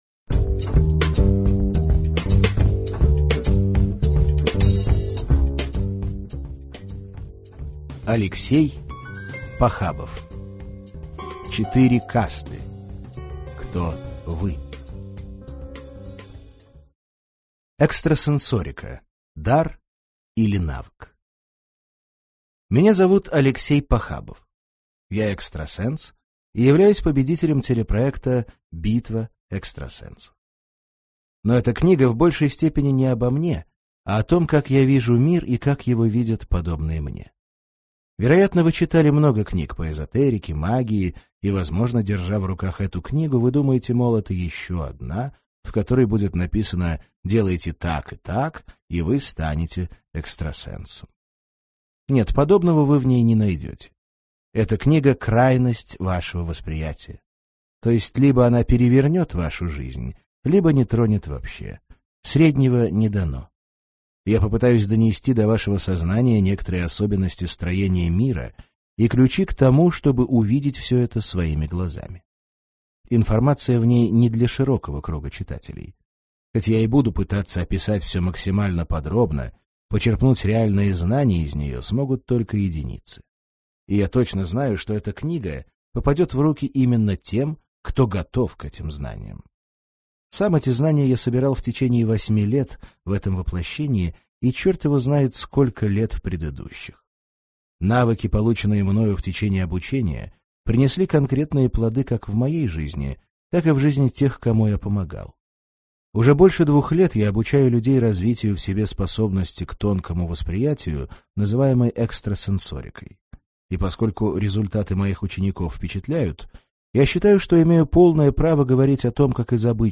Аудиокнига Четыре касты. Кто вы?